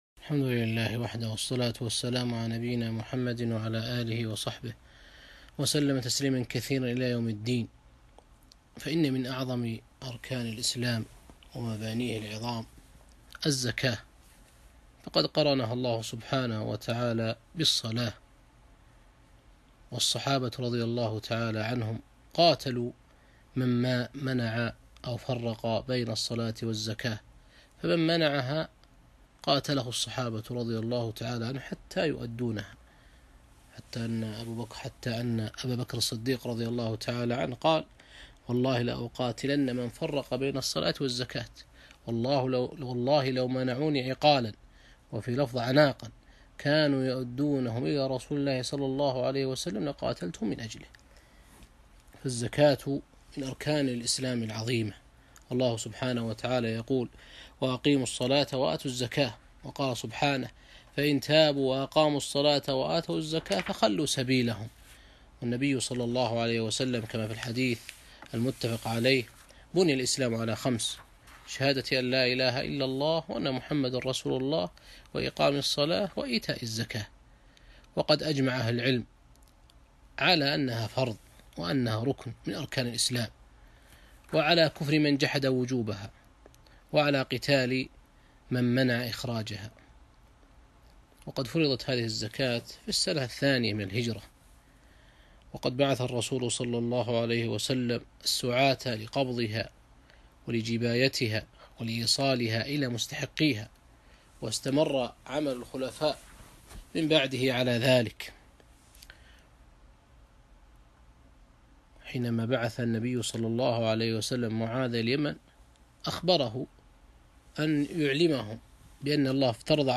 محاضرة - مسائل مهمة في زكاة المال